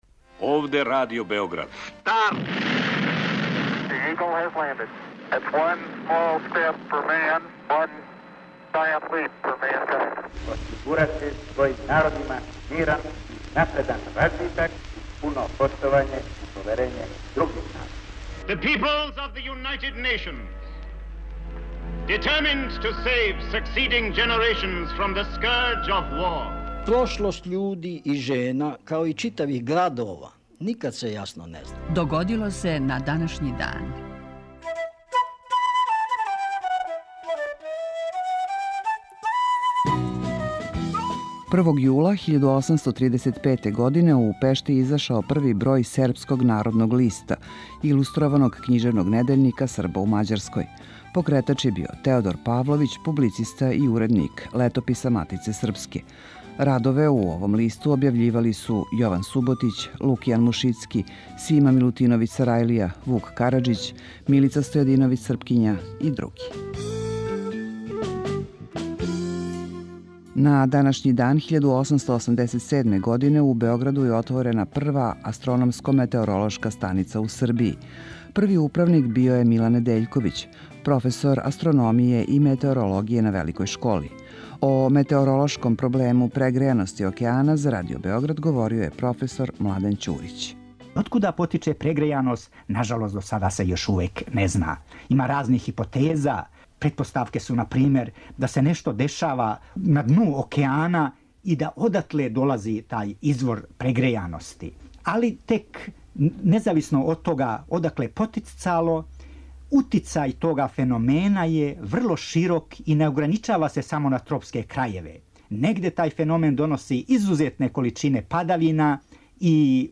У 5-томинутном прегледу, враћамо се у прошлост и слушамо гласове људи из других епоха.